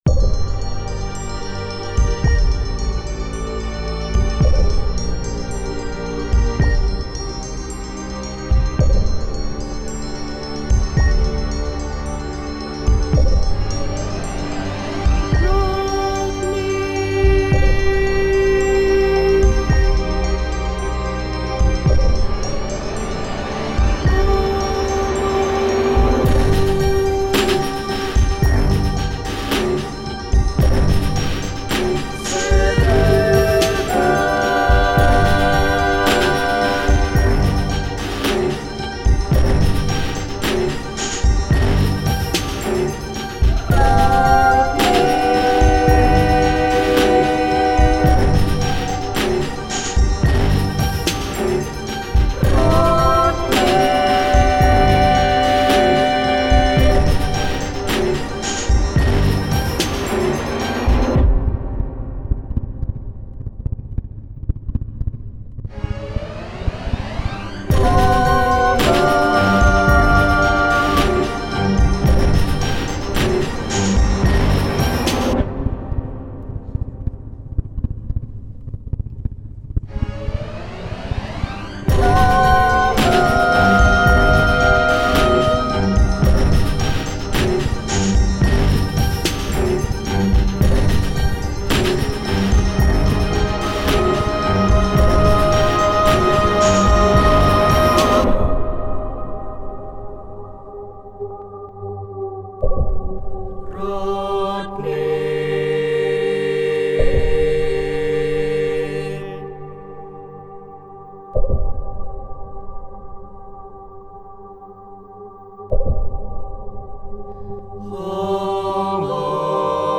Rakkaus, kuolema ja huumeriippuvaisuudet ovat tämän vahvan näytelmän teemoja. Näyttämösovitukset ovat usein olleet yhtä raakoja ja väkivaltaisia kuin itse tekstikin, mutta Circus Maximus valitsi toisen näkökulman: esityksen tyylilaji oli eräänlainen ultramoderni kamariooppera. KATSO VIDEO (2,4Mb Quicktime) MUSIIKKIA CLEANSED:ISTA (5,47Mb Mp3)